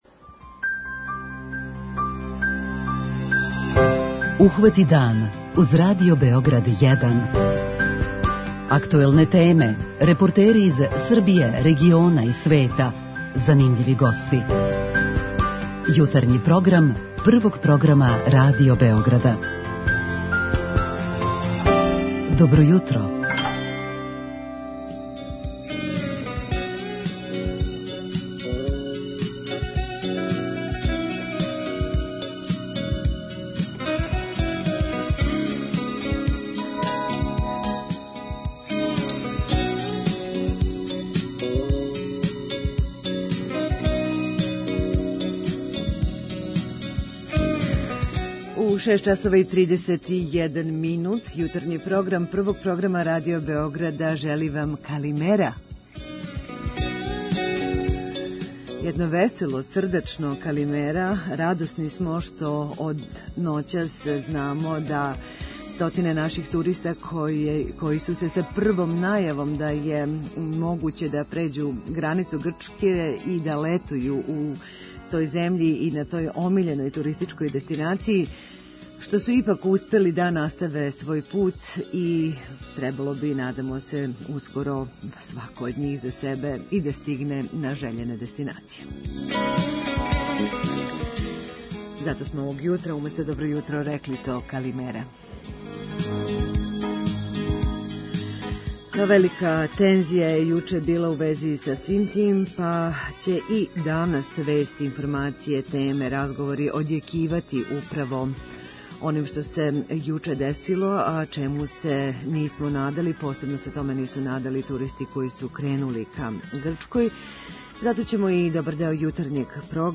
У Јутарњем програму о овој неочекиваној ситуацији говорићемо из више углова - јавиће нам се репортери са граничних прелаза према Бугарској и Северној Македонији, чућемо изјаве путника који су пошли, али не и стигли у Грчку, проверићемо да ли је ноћ за нама ипак донела неке промене у ставу грчких власти, као и како реагују туристичке агенције које су путовања организовале.